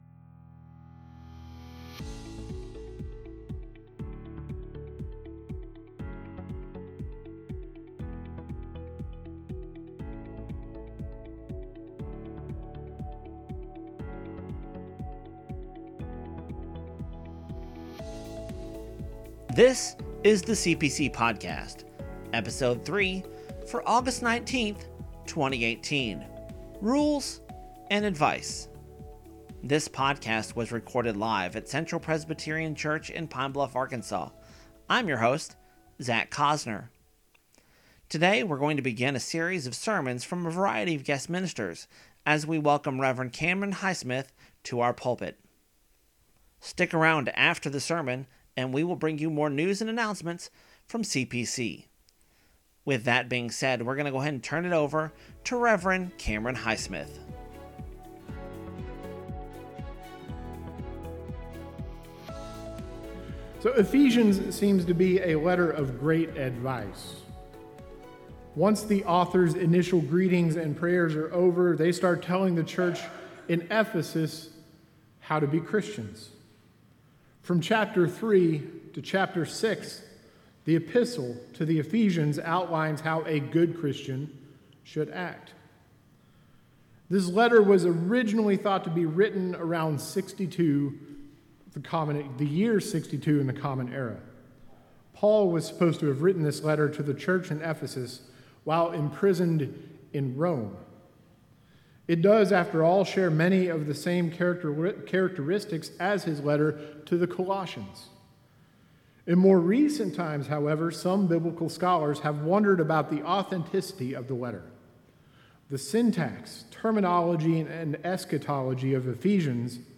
begins a sermon series of guests ministers on the podcast.